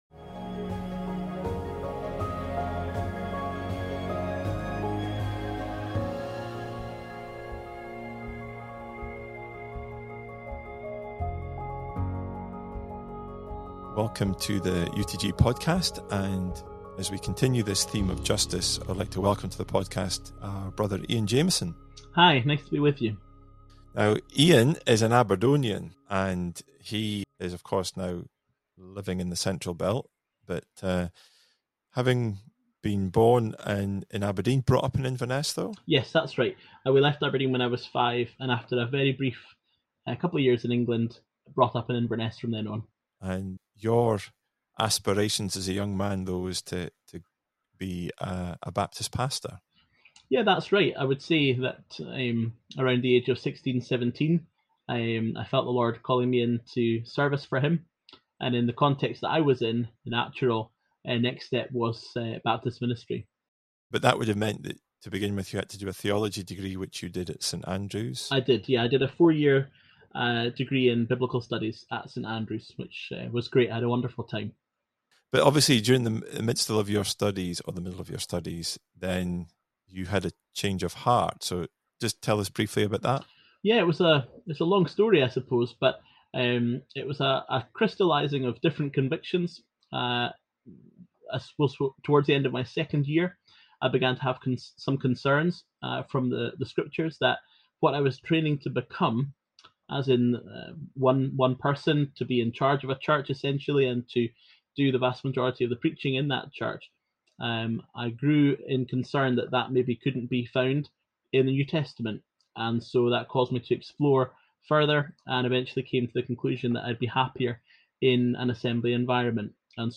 This is part 3 in a series of conversations about Justice.